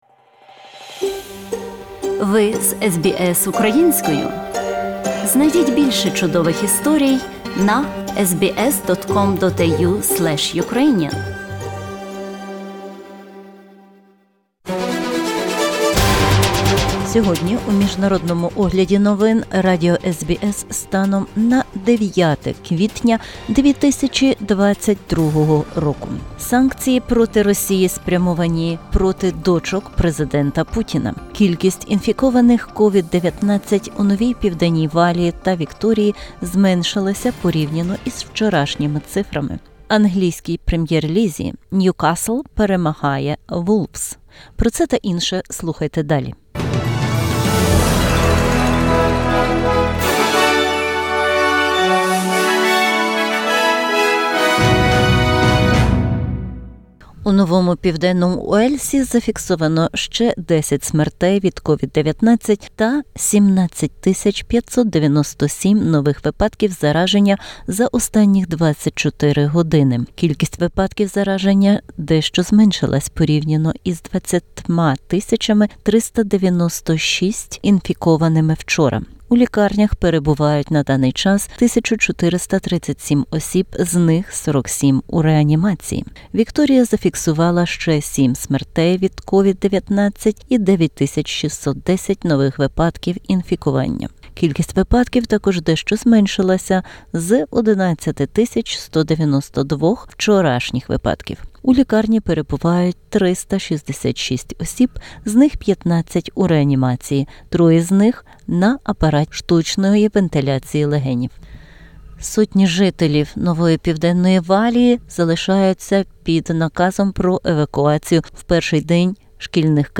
Бюлетень SBS новин українською мовою. Санкції проти Росії, у тому числі й спрямовані проти дочок президента Путіна. Кількість інфікованих COVID-19 у Новій Південній Валії та Вікторії зменшилася порівняно з вчорашніми цифрами.